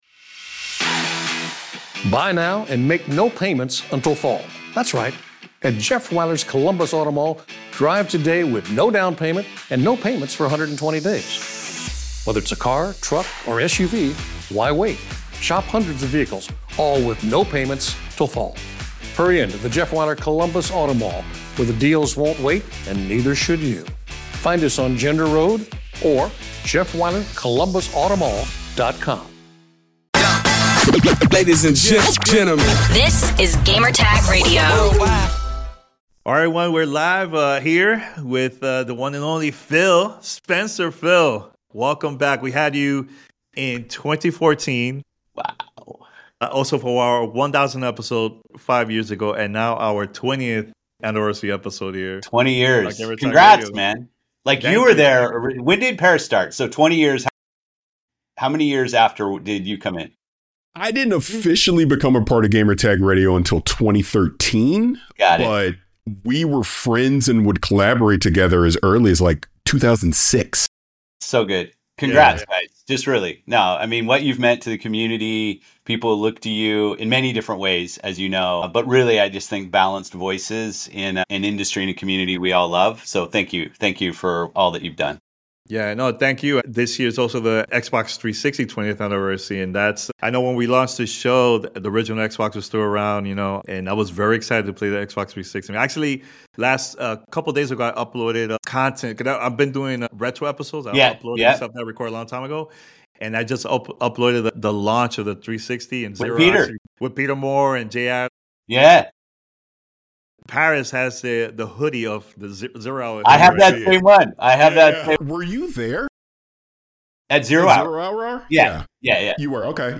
Interview with The CEO of Microsoft Gaming, Phil Spencer.